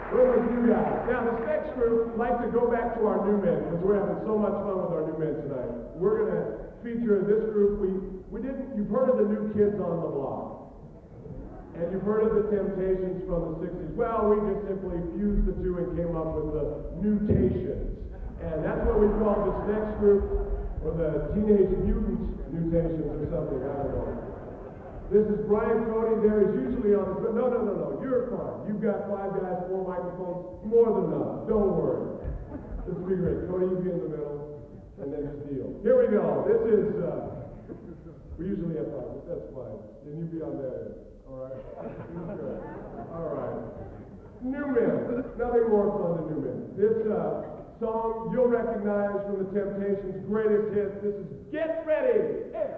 Collection: South Bend 1990